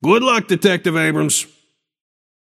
Shopkeeper voice line - Good luck, detective Abrams.
Shopkeeper_hotdog_t4_abrams_01.mp3